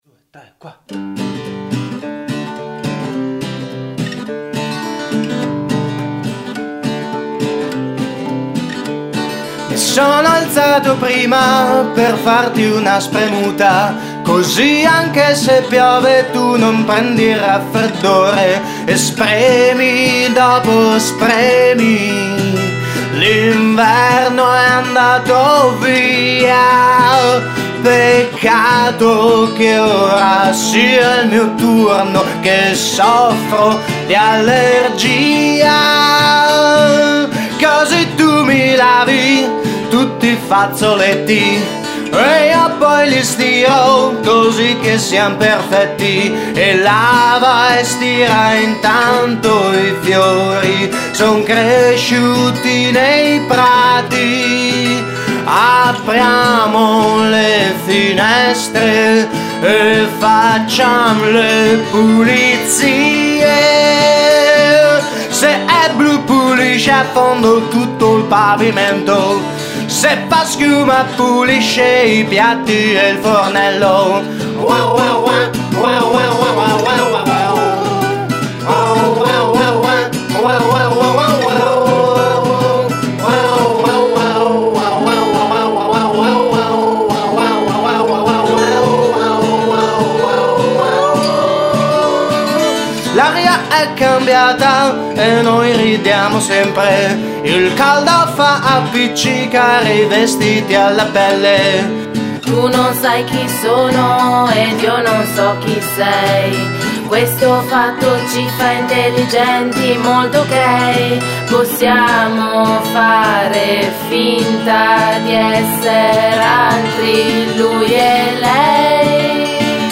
Luogo esecuzioneparma - studio BdC